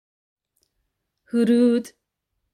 Huruud (Hurdi) / Yellow